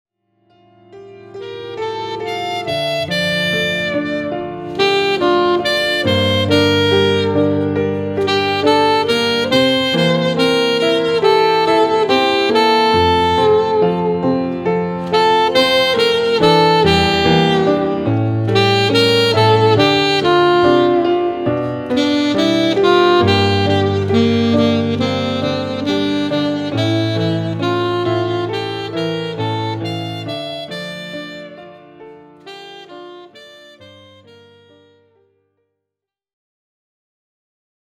スリリングながらも暖かいJAZZ愛に溢れるDuo作品！
Alto Sax
Piano